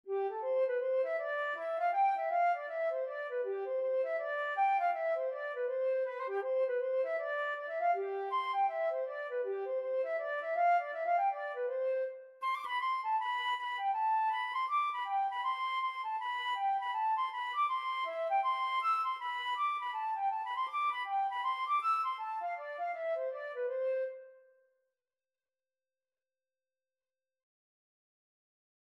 C major (Sounding Pitch) (View more C major Music for Flute )
4/4 (View more 4/4 Music)
G5-E7
Flute  (View more Intermediate Flute Music)
Traditional (View more Traditional Flute Music)
Irish